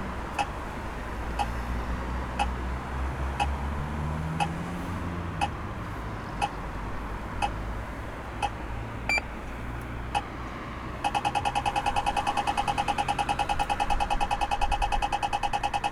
스웨덴의 보행자 음향 신호
크로아티아, 덴마크, 스웨덴에서는 "건너지 마세요" 모드를 나타내는 긴 간격의 삑 소리(또는 클릭 소리)와 "건너세요" 모드를 나타내는 매우 짧은 간격의 삑 소리가 사용된다.